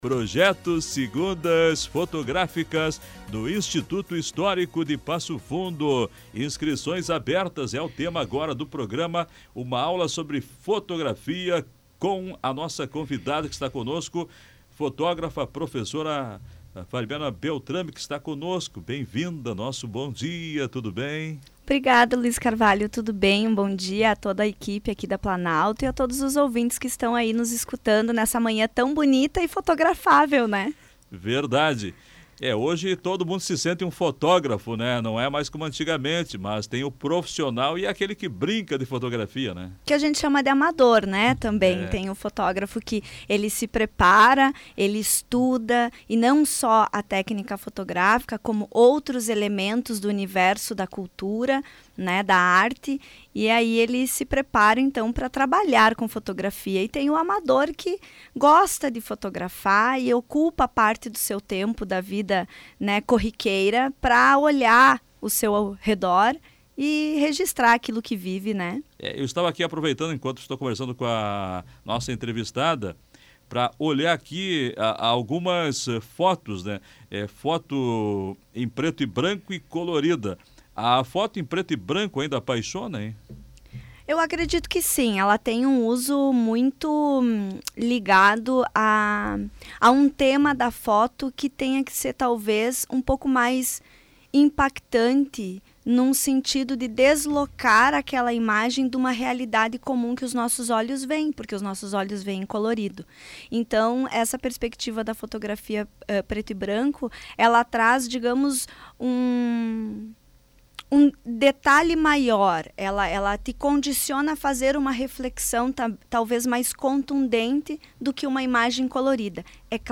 participou do programa Comando Popular, da Rádio Planalto News (92.1).